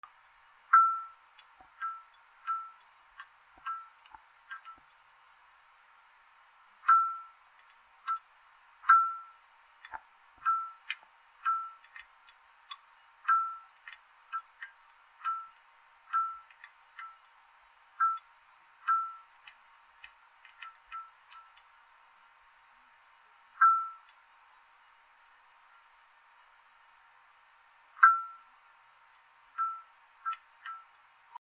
切れかけた蛍光灯